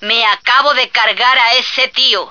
flak_m/sounds/female1/est/F1slaughter.ogg at 46d7a67f3b5e08d8f919e45ef4a95ee923b4048b